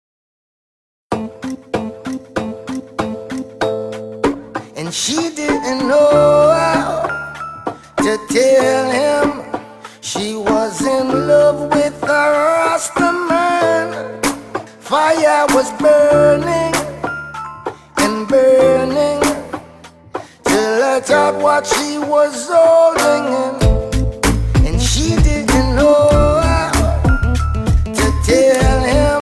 Description: Reggae